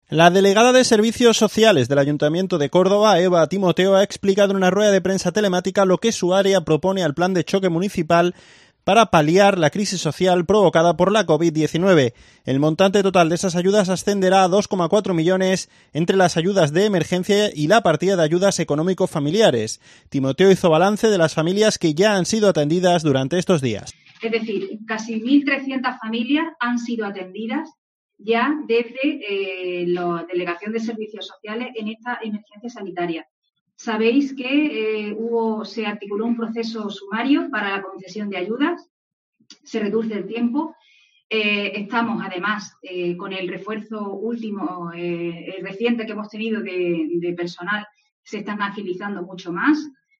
La responsable municipal de Servicios Sociales del Ayuntamiento de Córdoba, Eva Timoteo, ha explicado este miércoles en una rueda de prensa telemática la labor que se está llevando a cabo en las últimas semanas en su área para “reconstruir una ciudad que se ha roto por completo".